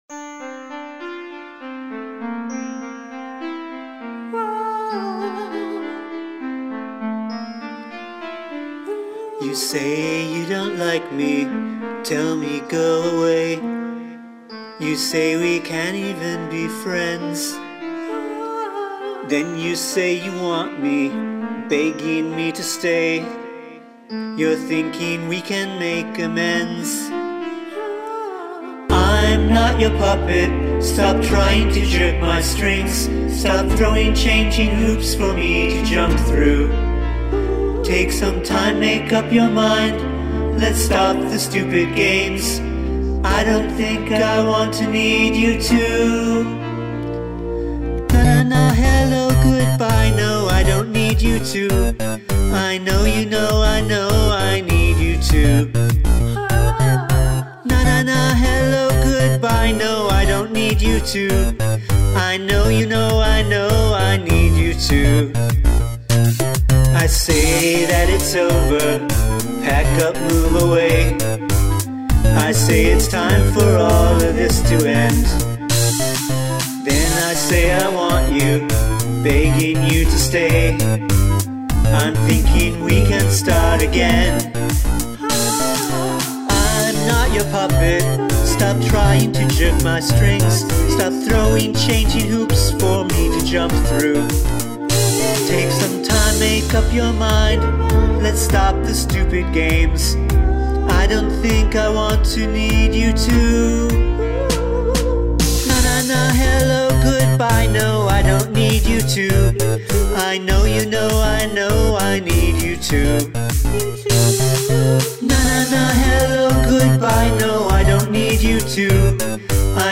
Contrafact chorus or contrafact verse(s)